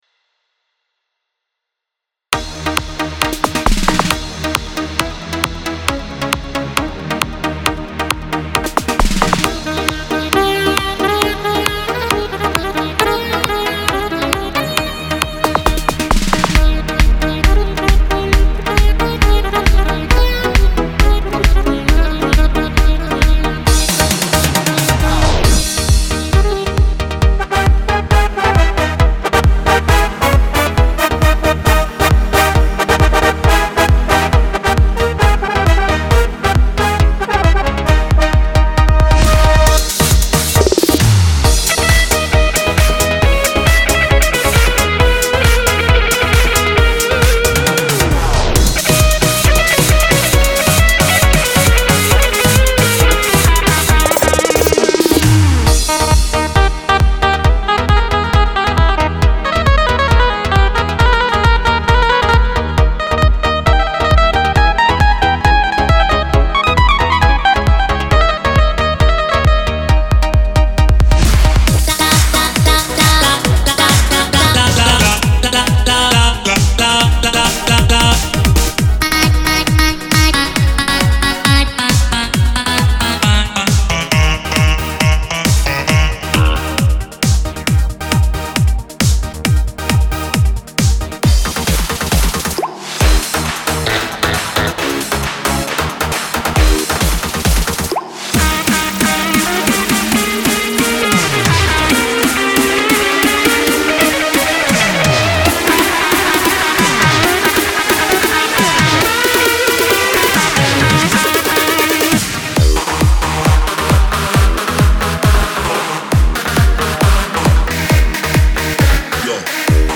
מקצבים לקורג